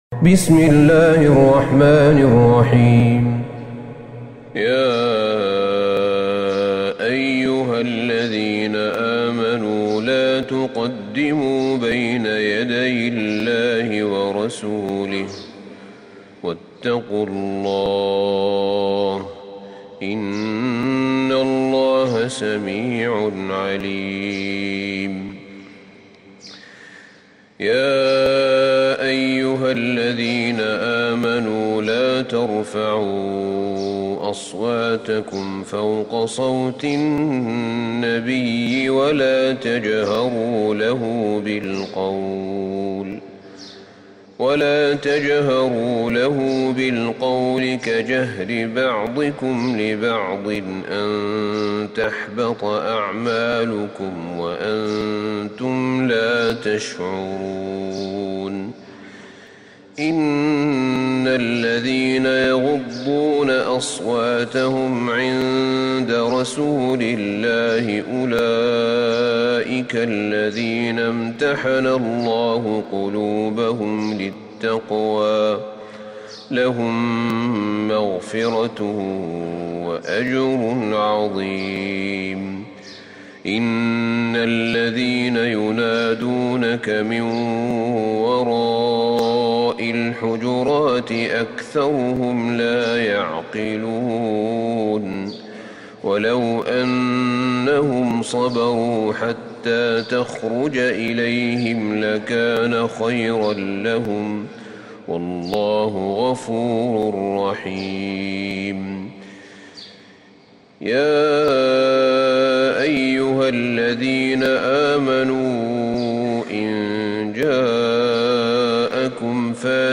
سورة الحجرات Surat Al-Hujurat > مصحف الشيخ أحمد بن طالب بن حميد من الحرم النبوي > المصحف - تلاوات الحرمين